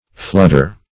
Flooder \Flood"er\, n. One who floods anything.